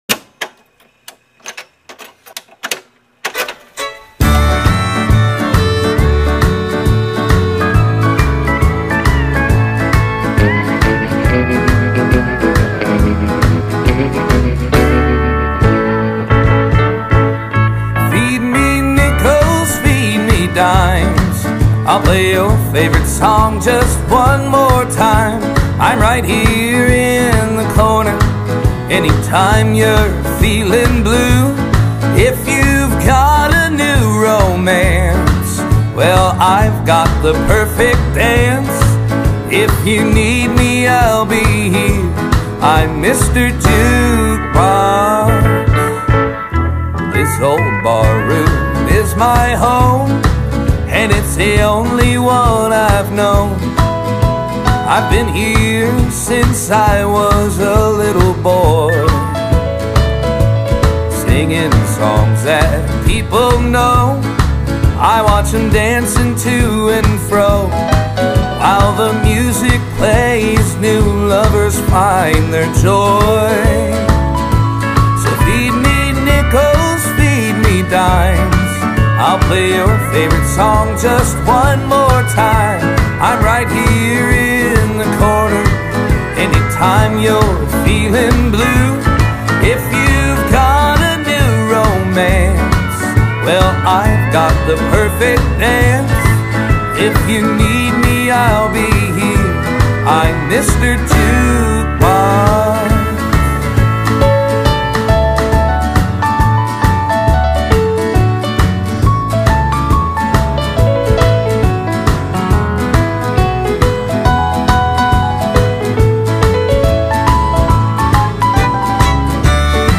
heartbreaking, distilled, defiantly classic Country
a salve and beacon for '60's Honky-Tonk devotees everywhere.